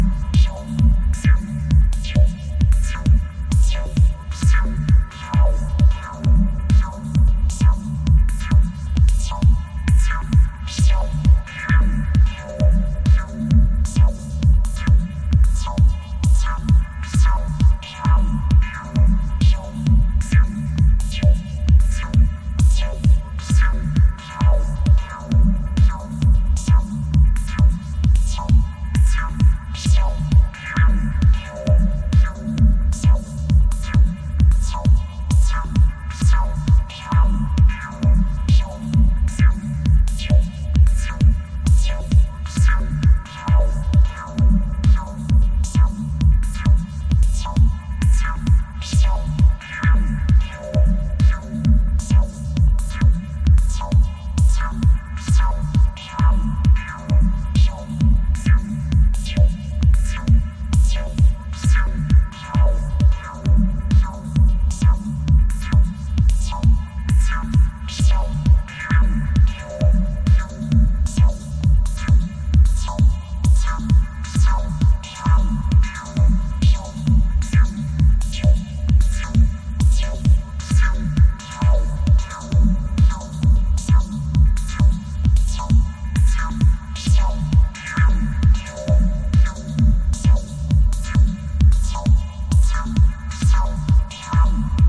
ほぼアナログ機材、ライブ録音で制作される実験ミニマル作品。